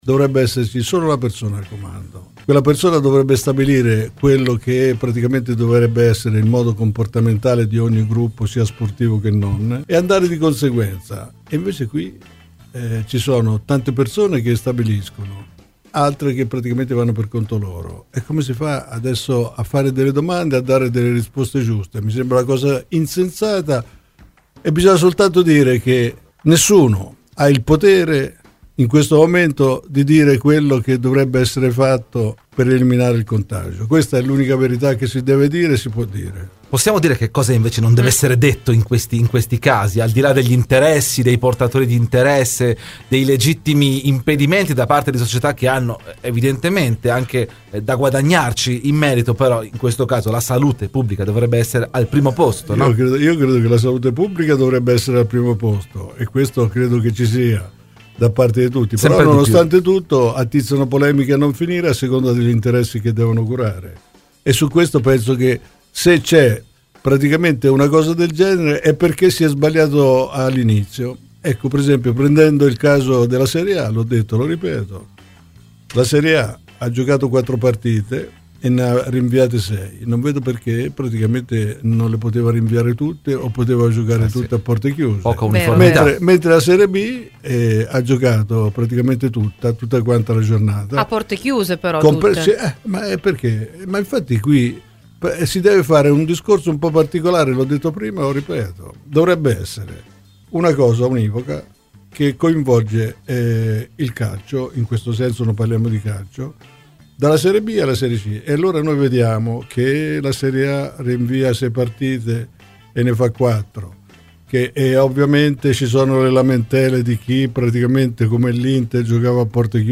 Queste le parole di Luciano Moggi, ai microfoni di Radio Bianconera durante la trasmissione "Terzo tempo."